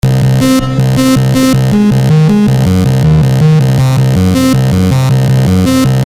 Flowing Bass
描述：A nice bass tune with some complex moving.
标签： 160 bpm Electro Loops Bass Loops 1.01 MB wav Key : Unknown
声道立体声